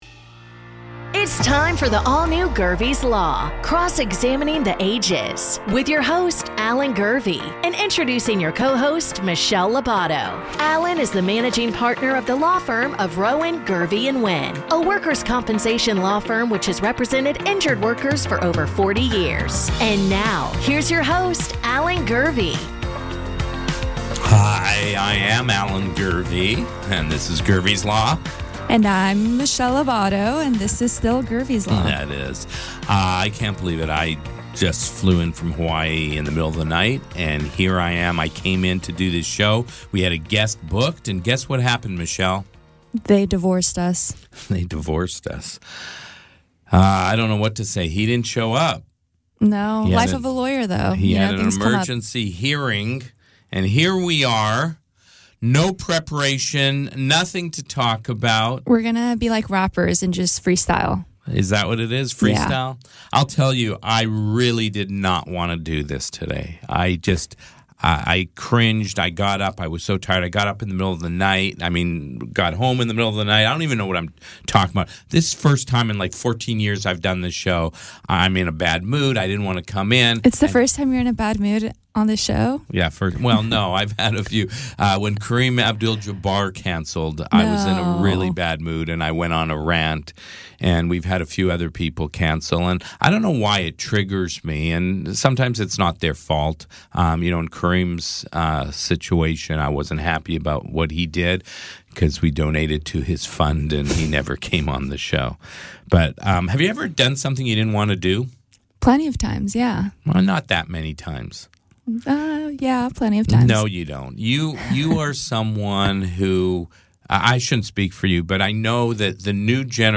Do I have to? A philosophical conversation